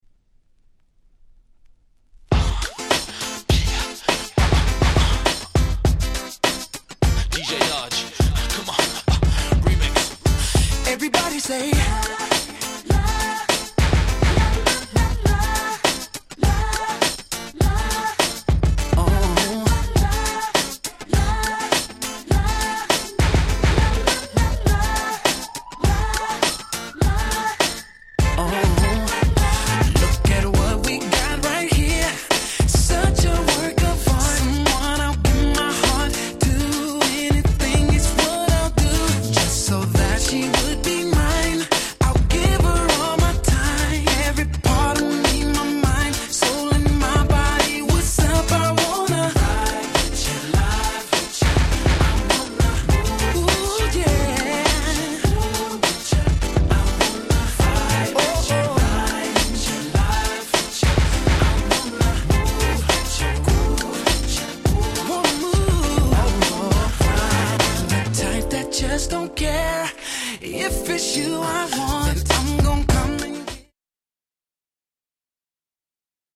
04' Big Hit R&B !!